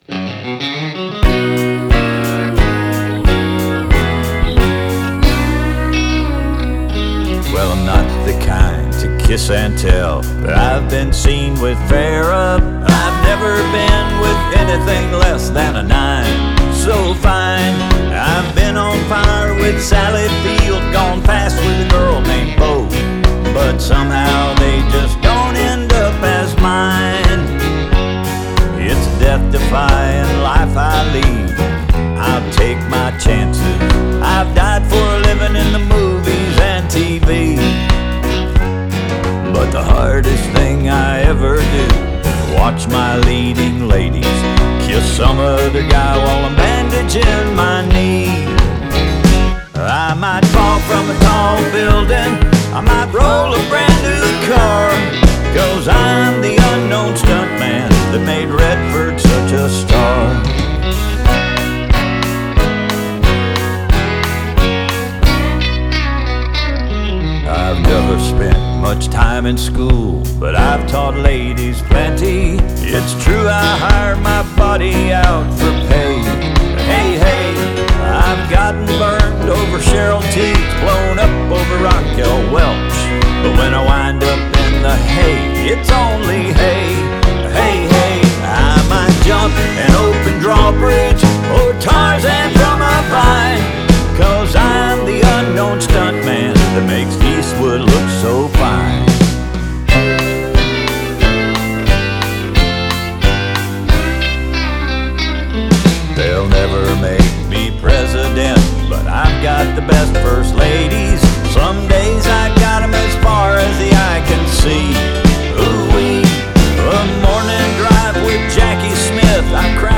Ansonsten ist der Gesang natürlich erste Sahne.
- Die Kick drückt / pumpt ziemlich.